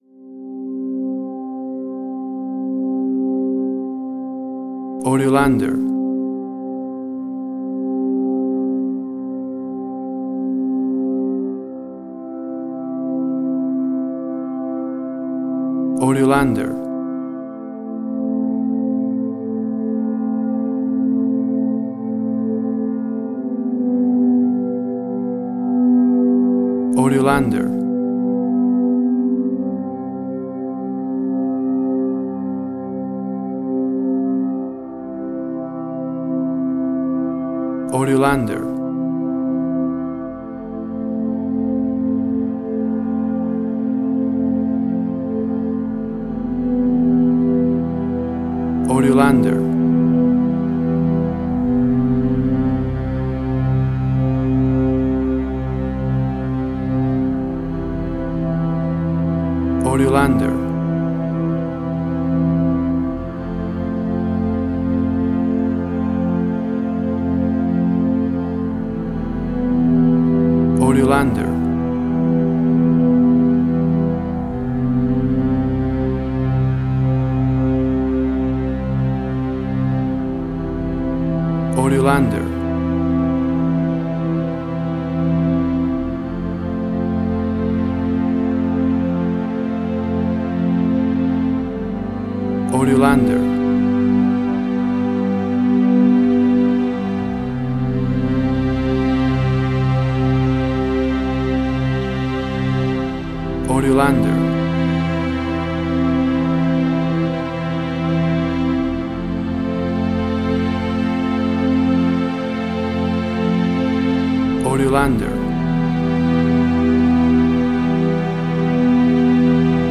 Suspense, Drama, Quirky, Emotional.
WAV Sample Rate: 16-Bit stereo, 44.1 kHz